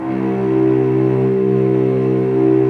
Index of /90_sSampleCDs/Roland LCDP13 String Sections/STR_Vcs II/STR_Vcs6 p Amb